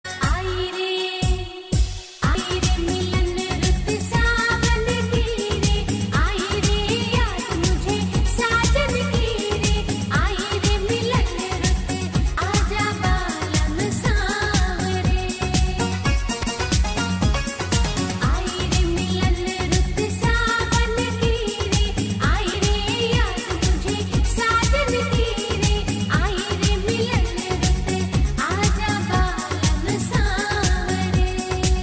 File Type : Navratri dandiya ringtones